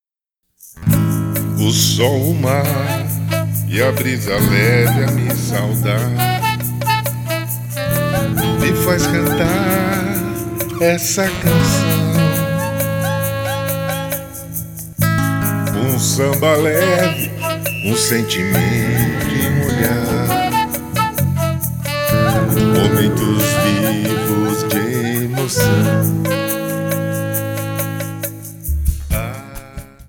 Saxofone/Flauta
Baixo/Violão/Apito
Bateria